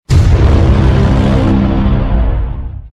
Category: Sound FX   Right: Both Personal and Commercial
Tags: meme sound; meme effects; youtube sound effects;